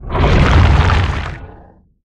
File:Sfx creature shadowleviathan swimgrowl os 01.ogg - Subnautica Wiki
Sfx_creature_shadowleviathan_swimgrowl_os_01.ogg